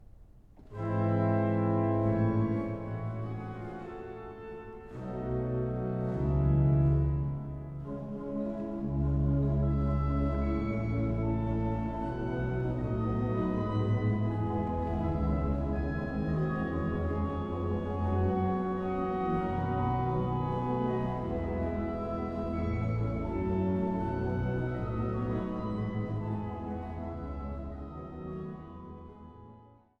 Große Sauer-Orgel der St. Johannes Kirche